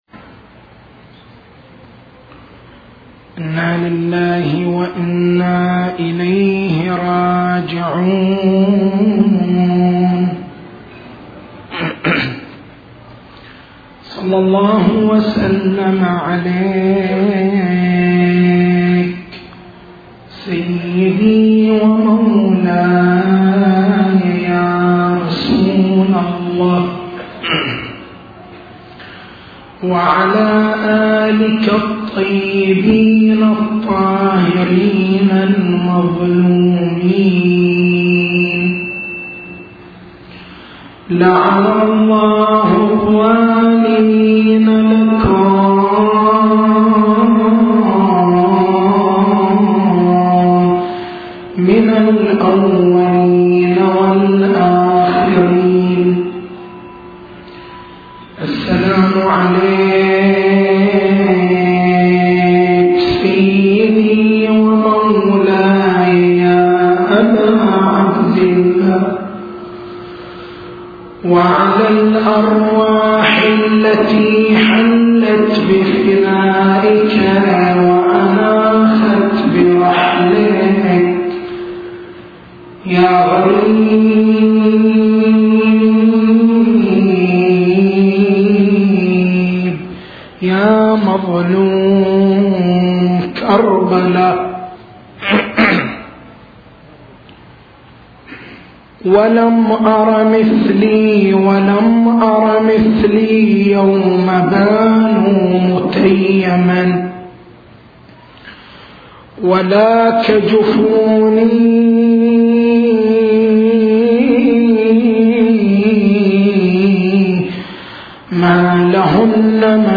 فائدة إمامة الإمام المهدي (عج) نقد نظرية الخبرة القيادية ضرورة الوساطة في الفيض دور الإمام في حفظ الكيان الشيعي التسجيل الصوتي: تحميل التسجيل الصوتي: شبكة الضياء > مكتبة المحاضرات > محرم الحرام > محرم الحرام 1427